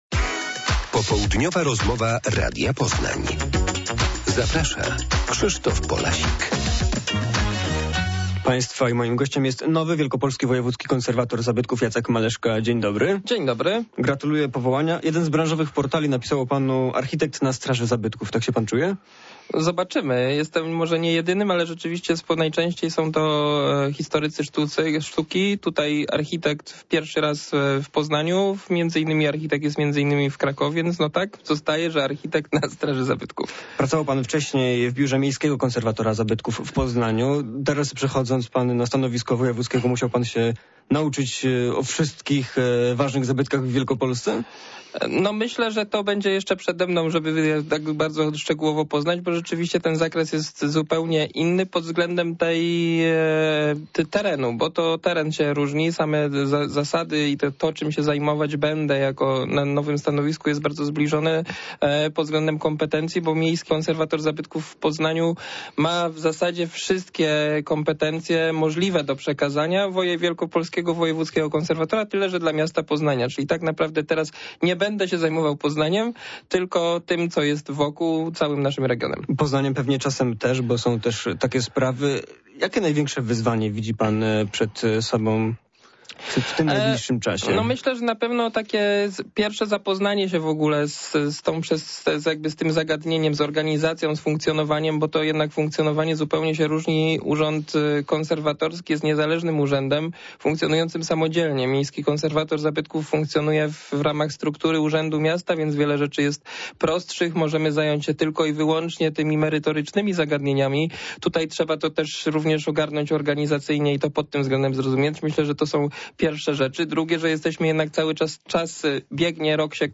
Popołudniowa rozmowa Radia Poznań – Jacek Maleszka
Wielkopolska ma nowego Wojewódzkiego Konserwatora Zabytków. Został nim Jacek Maleszka, który w popołudniowej rozmowie odpowiada na pytania o najgłośniejsze kontrowersje związane z zabytkami z Poznania.